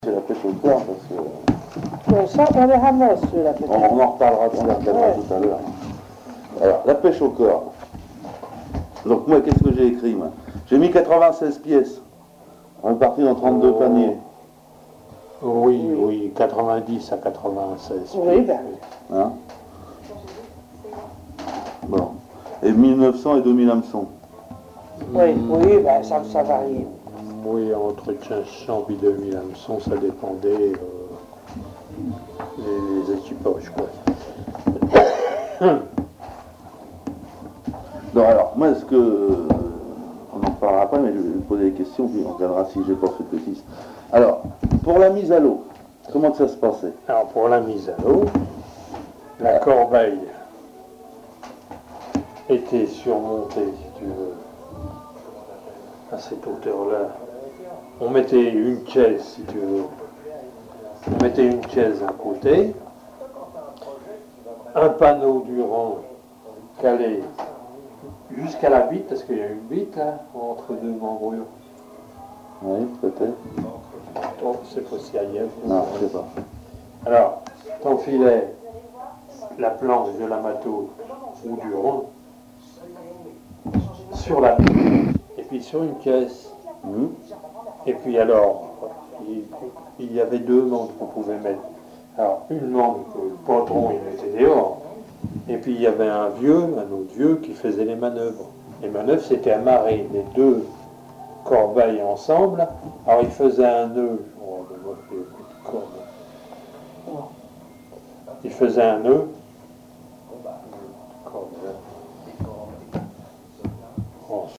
Entretien sur la pêche côtière à Yport
Catégorie Témoignage